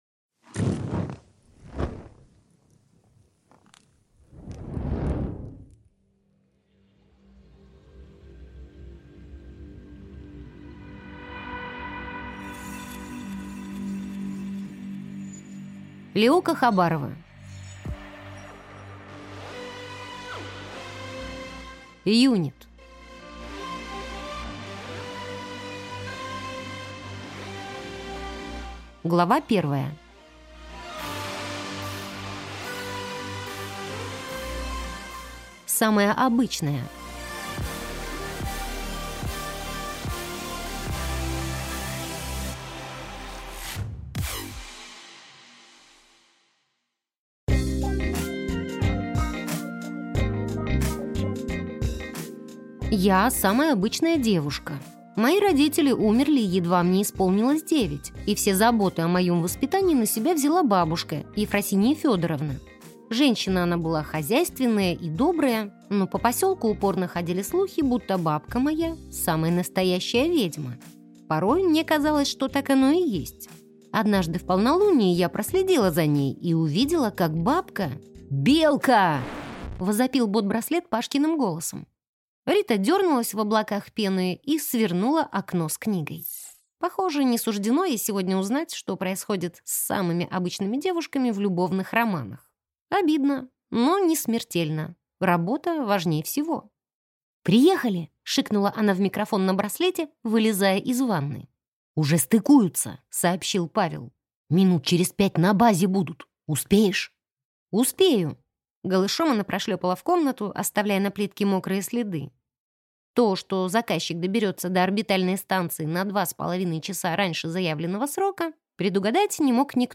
Аудиокнига Юнит | Библиотека аудиокниг
Прослушать и бесплатно скачать фрагмент аудиокниги